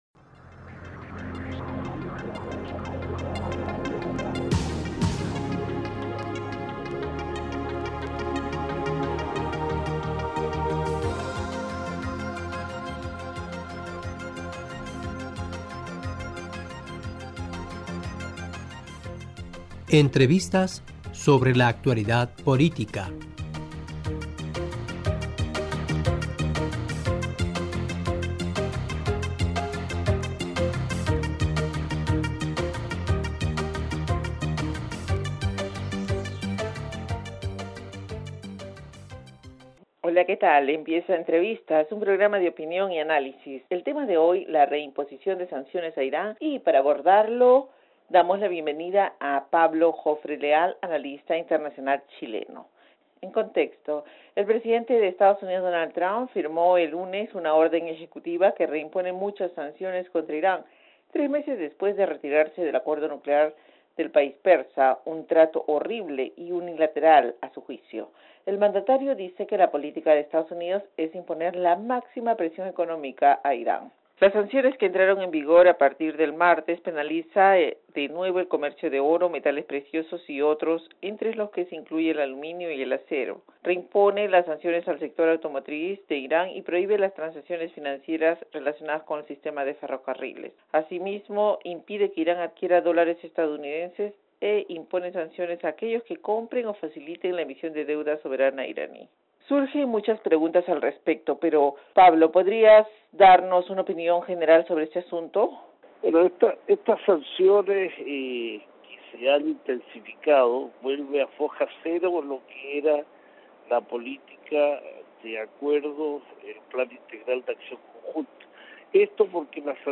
Empieza Entrevistas, un programa de opinión y análisis.